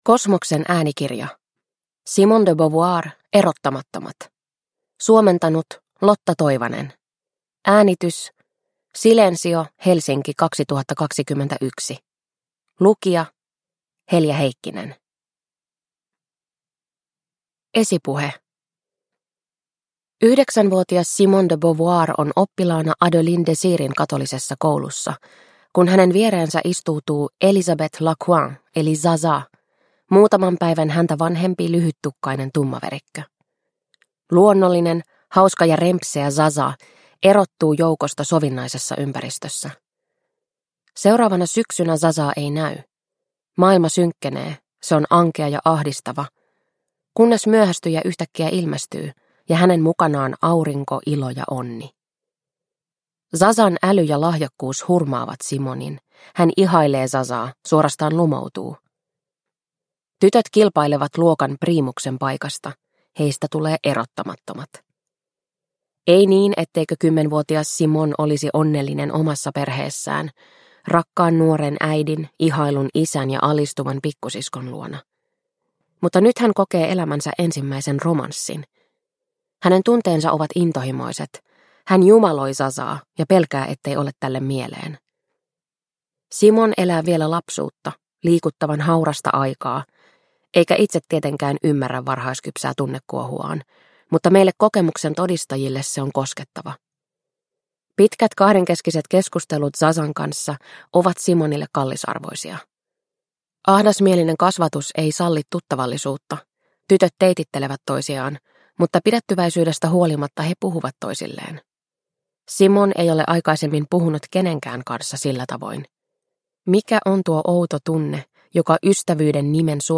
Erottamattomat – Ljudbok – Laddas ner